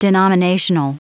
Prononciation du mot denominational en anglais (fichier audio)
Prononciation du mot : denominational